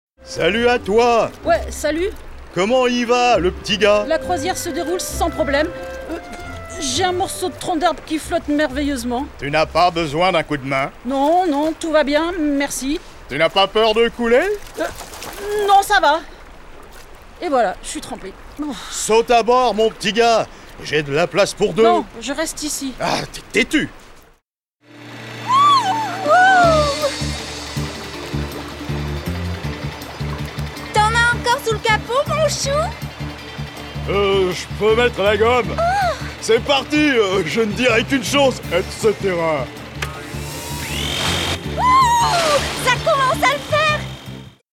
VOIX DOUBLAGE – FILM D’ANIMATION « Sacrés Rongeurs » (G.P.)